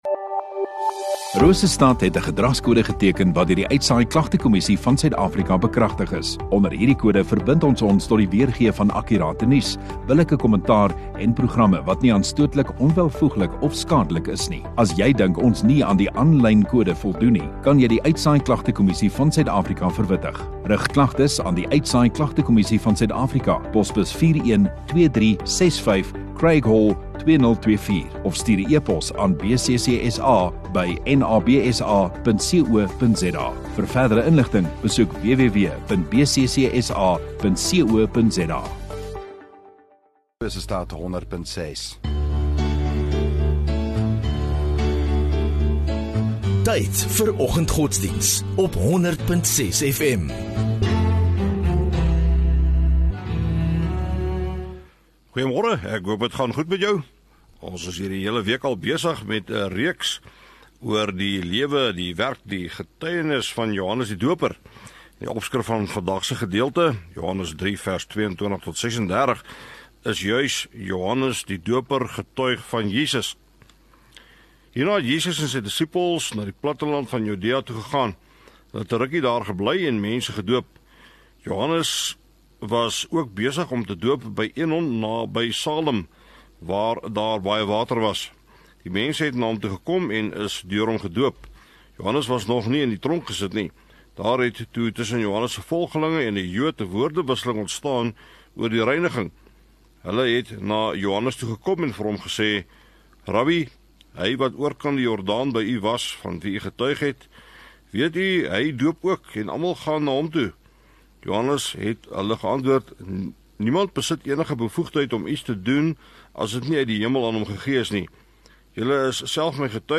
11 Jul Vrydag Oggenddiens